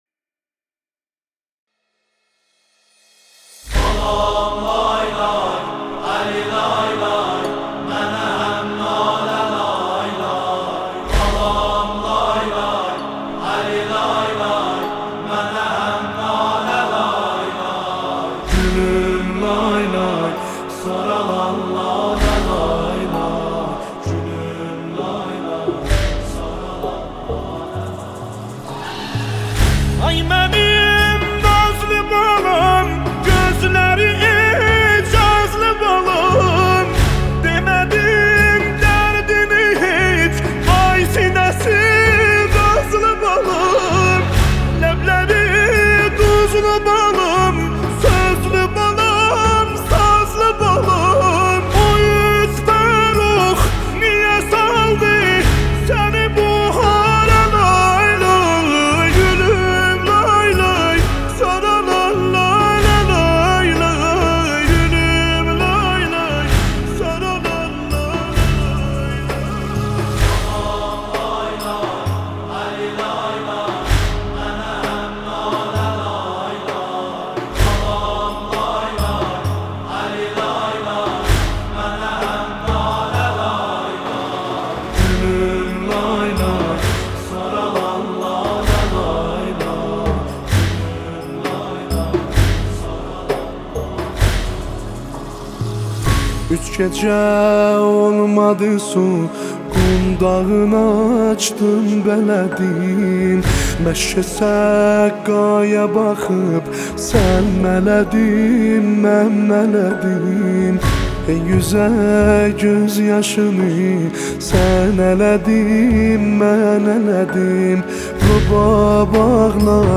نوحه آذری
نوحه ترکی